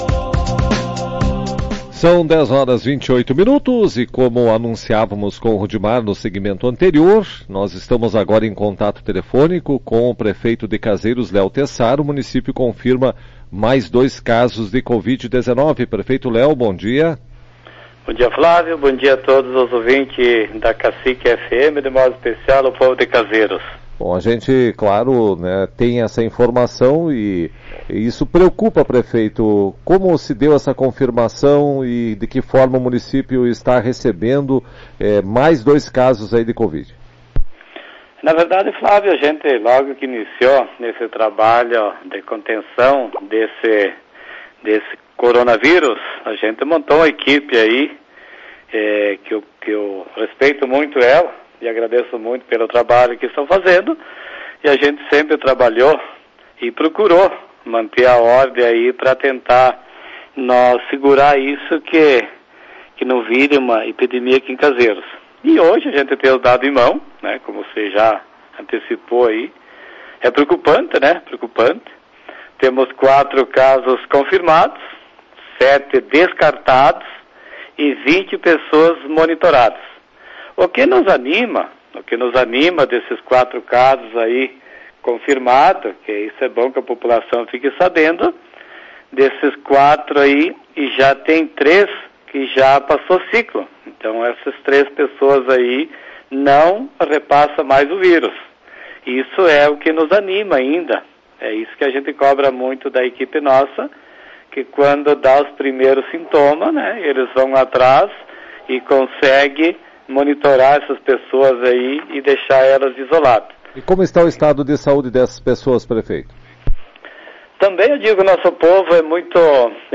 Em entrevista a Tua Rádio Cacique, o prefeito Leo Tessaro falou sobre as novas confirmações e disse que se acredita em contaminação comunitária.